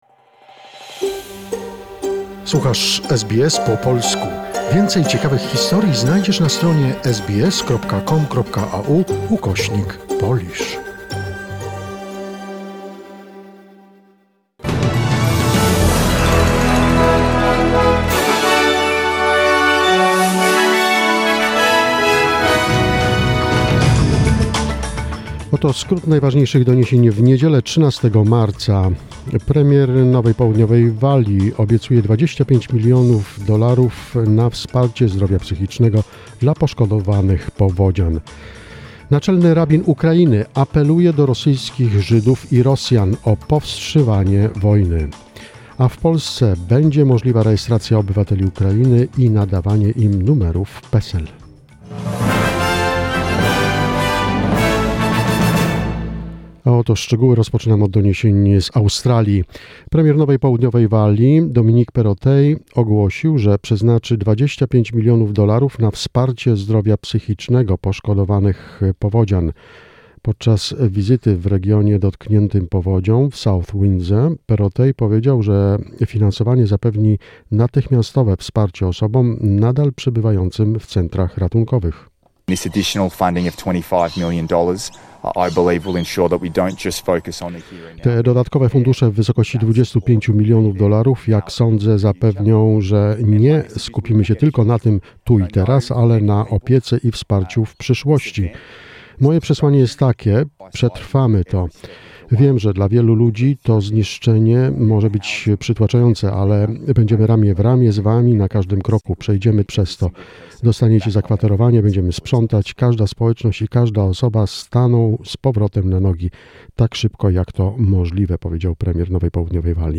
SBS News in Polish, 13 March 2022